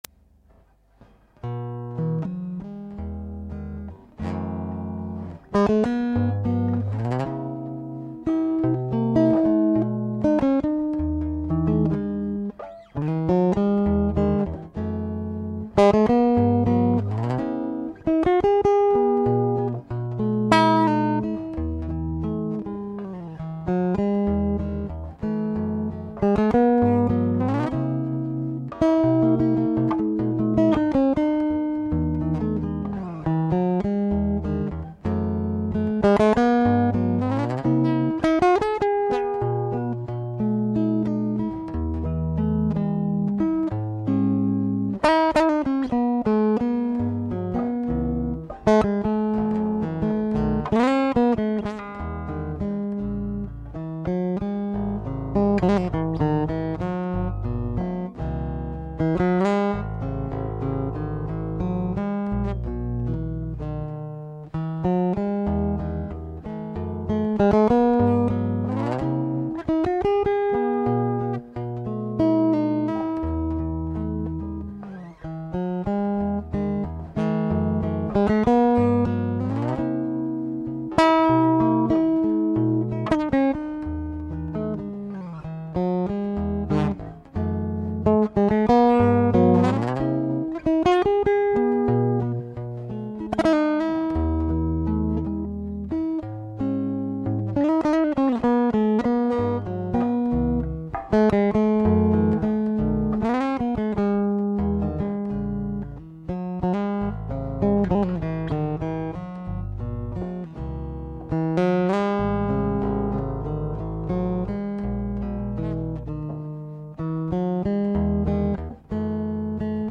Below you will find several sound clips performed on the Clement Anne #135 chambered bass.